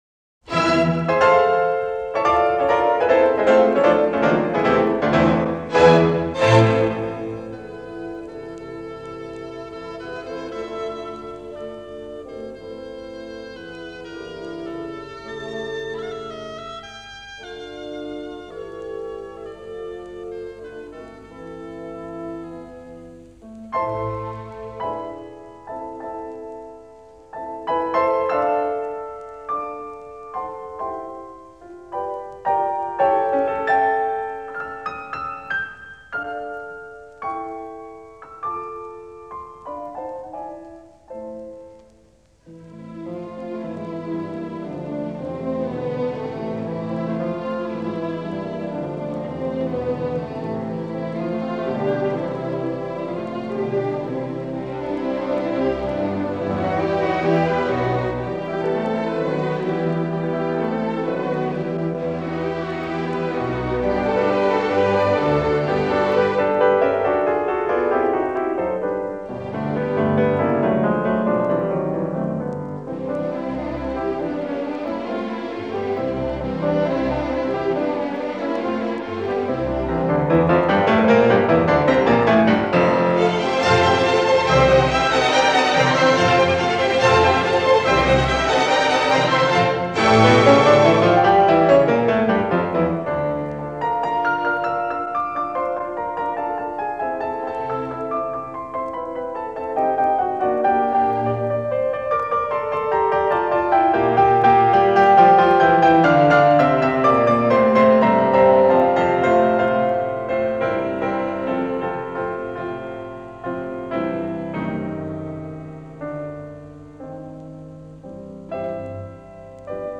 Schumann PC in A minor op. 54 I Allegro affettuoso.mp3